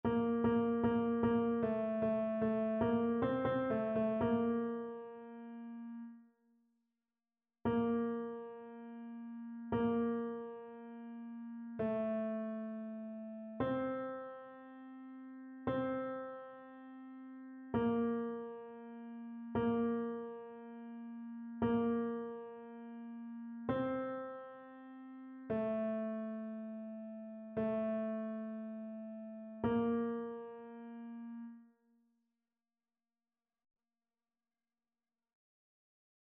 Paroles : AELF - Musique : JFD
annee-a-temps-ordinaire-6e-dimanche-psaume-118-tenor.mp3